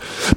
Kopie von VEC2 Beatbox#545D.wav